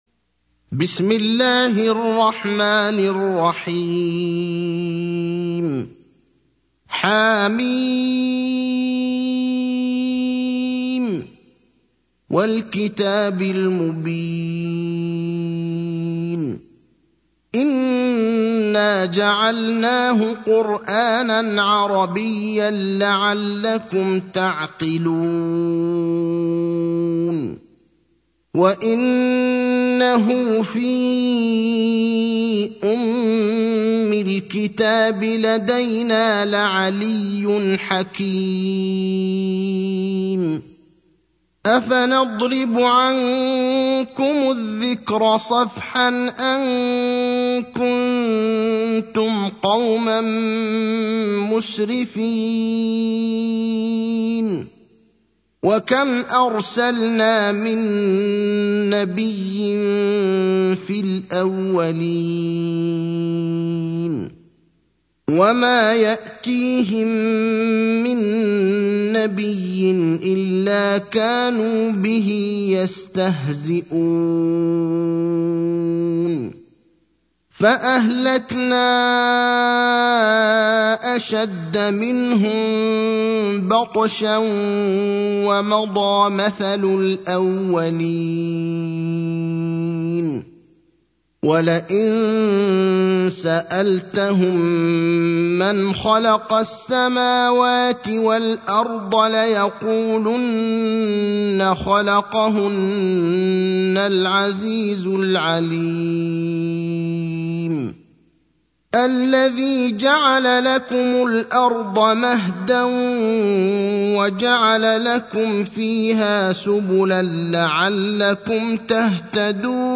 المكان: المسجد النبوي الشيخ: فضيلة الشيخ إبراهيم الأخضر فضيلة الشيخ إبراهيم الأخضر الزخرف (43)